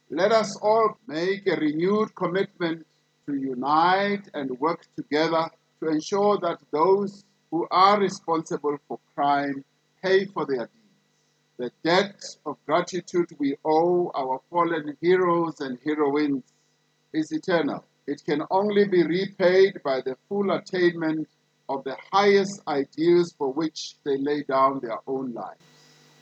During the annual South African Police Service Commemoration Day he said 34 officers have, between 1 April 2020 and 31 March 2021, been killed while on active duty.